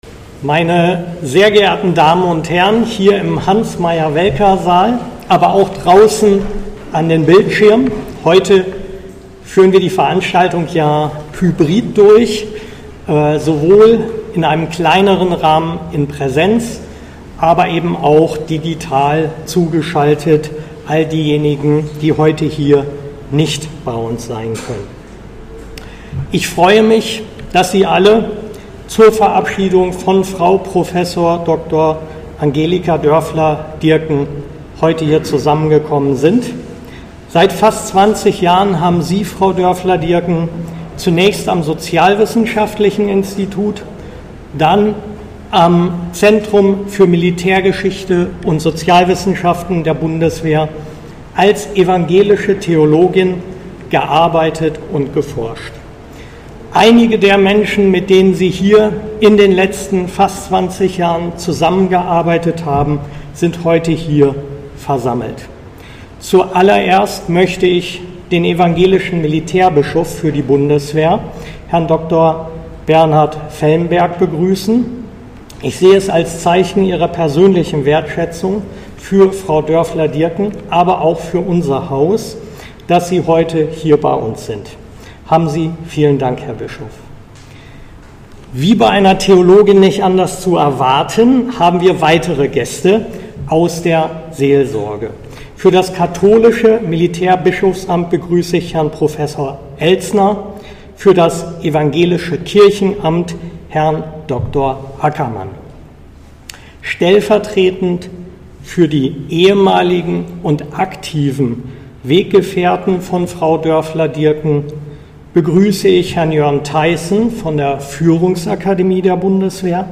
Abschied vom Team ZMSBwZentrum für Militärgeschichte und Sozialwissenschaften der Bundeswehr
Würdigung